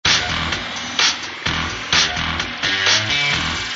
music_load_loop.mp3